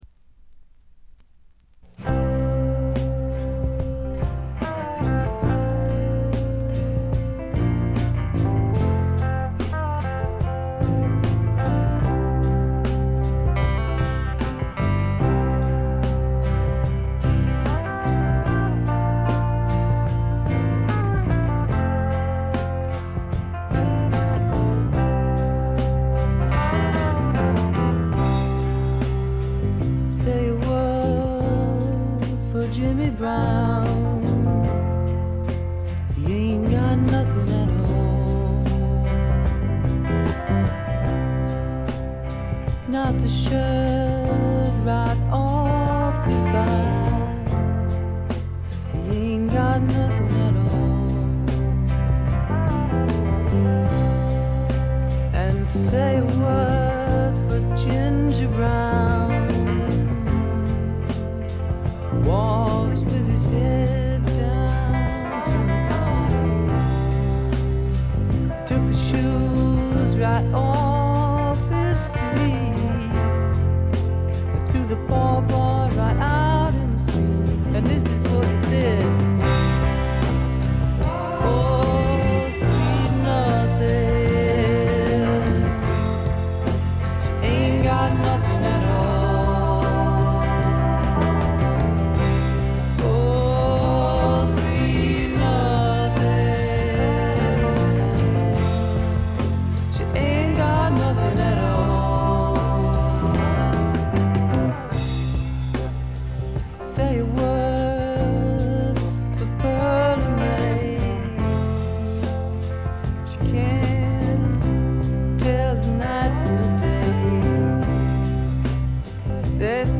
静音部で軽微ななチリプチが少々。
試聴曲は現品からの取り込み音源です。